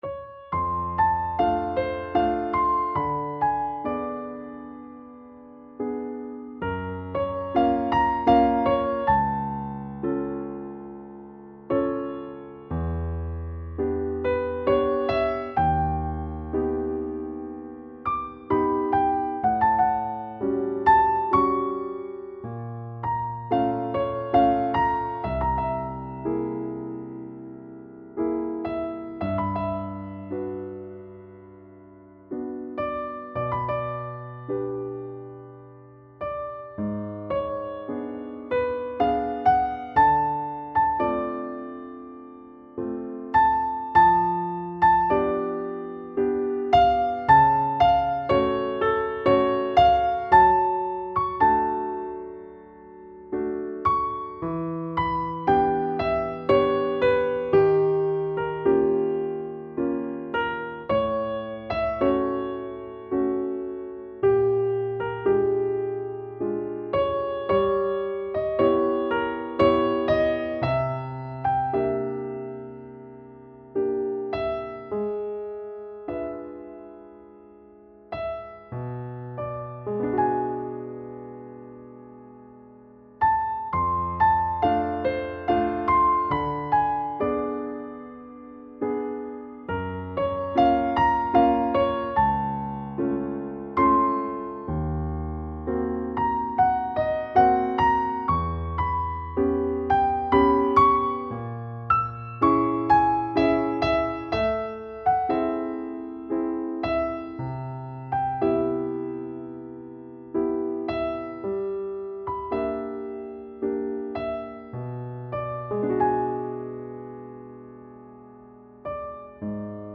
(version for piano)